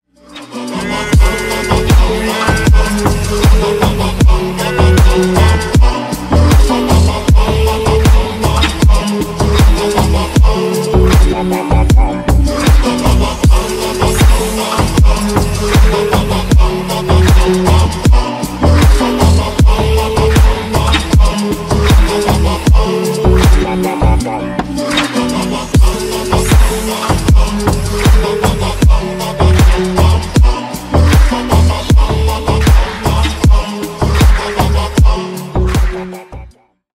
Ремикс
без слов